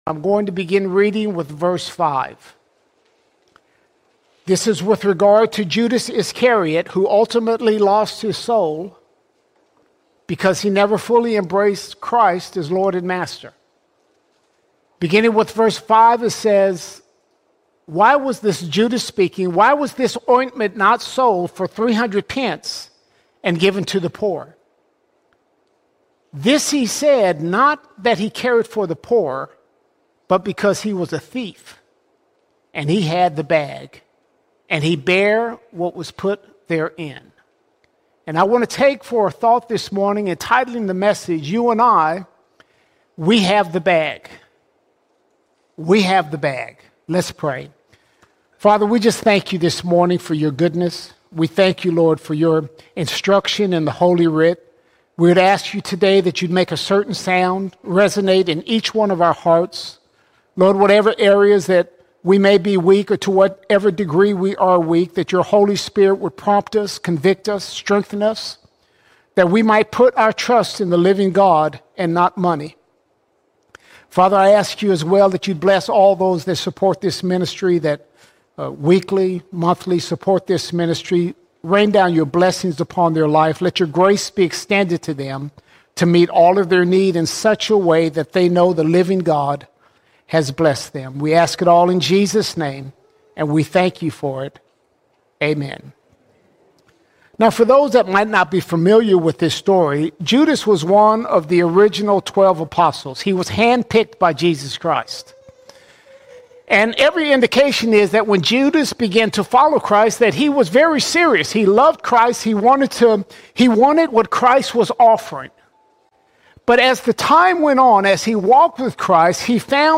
25 August 2025 Series: Sunday Sermons All Sermons We Have The Bag We Have The Bag We live in a generation where many are chasing after 'the bag.'